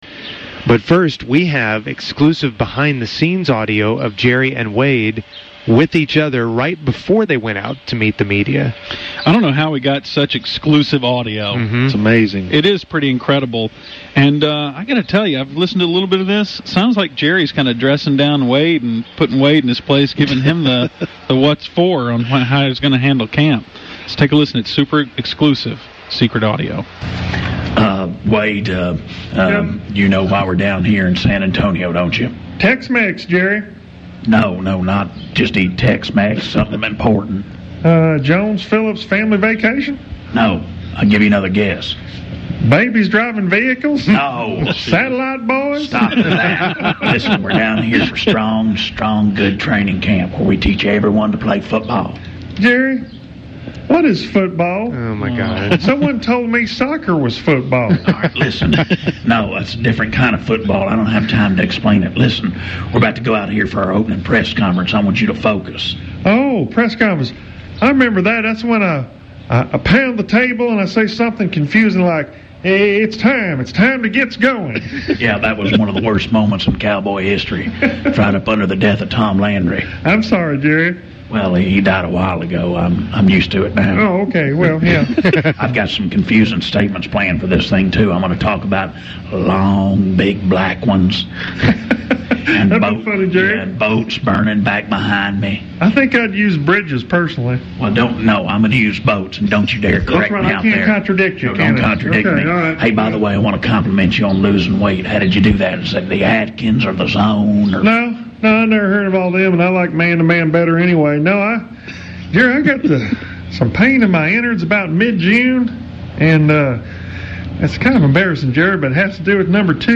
It’s great to hear these guys again because that means it’s time for a weekly 8:40 segment with Fake Jerry & Wade…oh yeah, and FOOTBALL!!